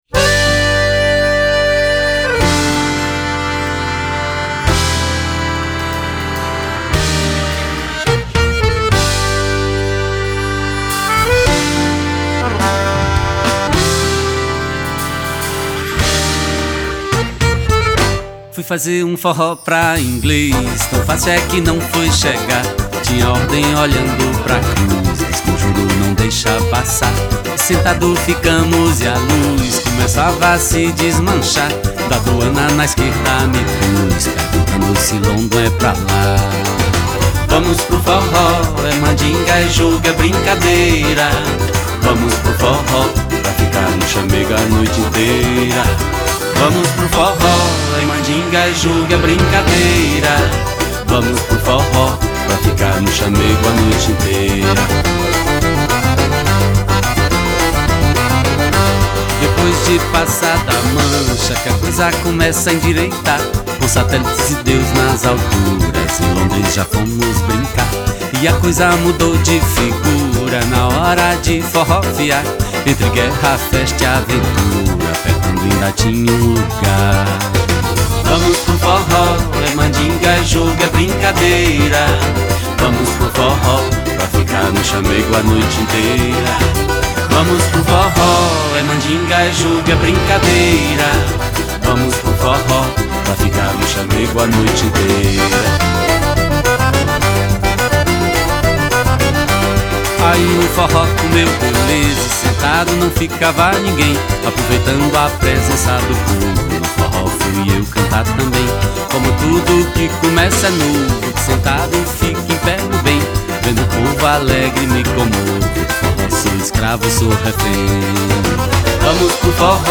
Genre : Forró